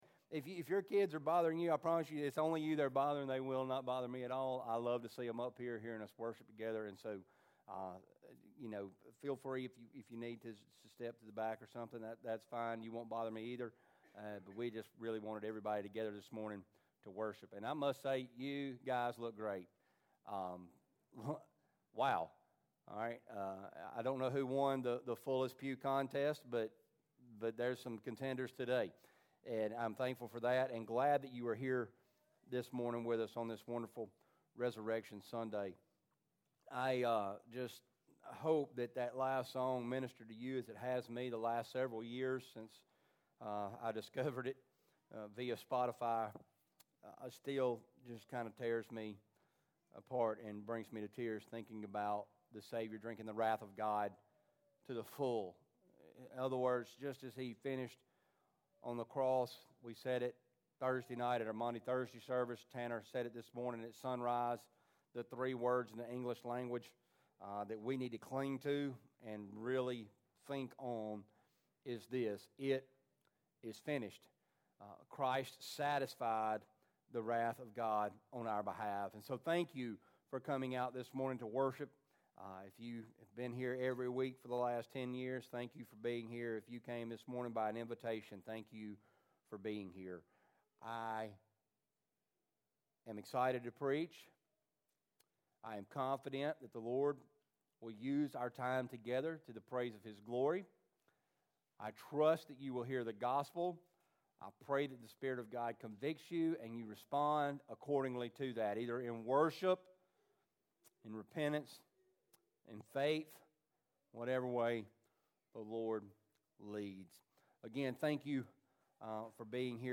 Easter Service - New Home Titus Church